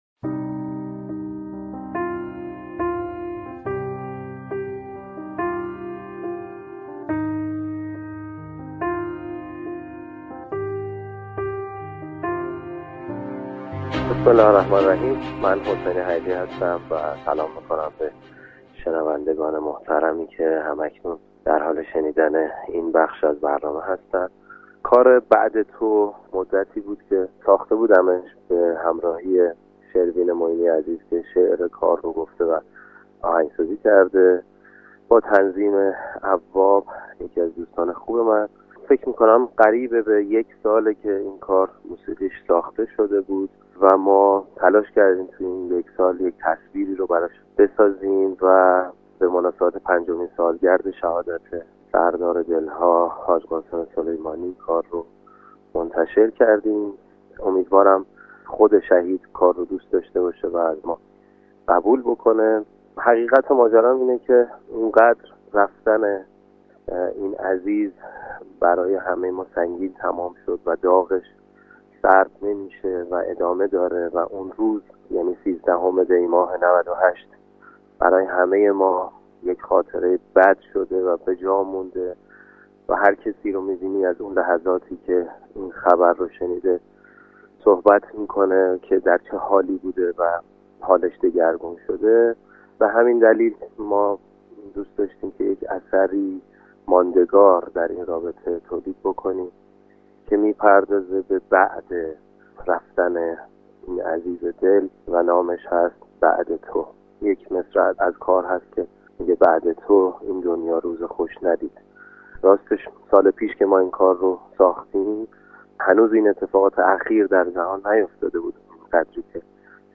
گفتگو با خواننده «بعد از تو »به یاد سردرار شهید حاج قاسم سلیمانی